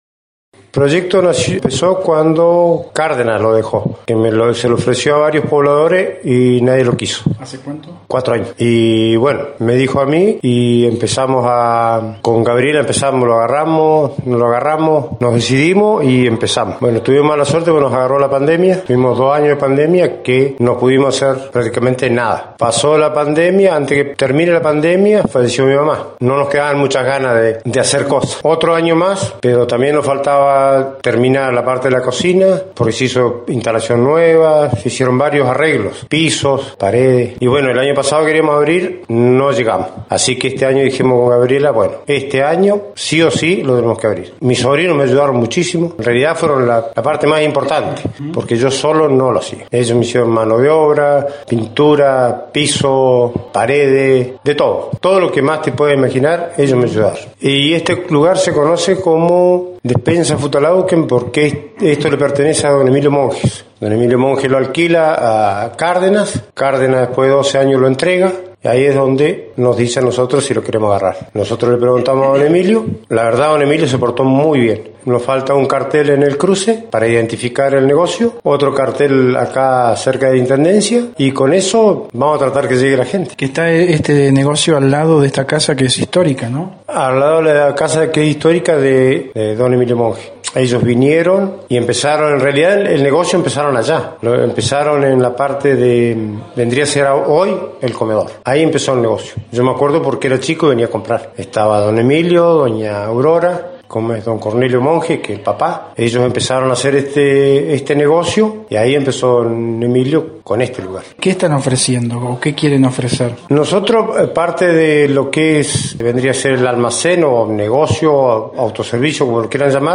El equipo de Noticias de Esquel fue invitado a la inauguración del local